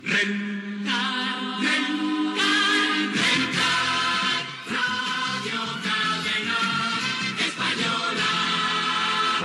Indicatiu emissora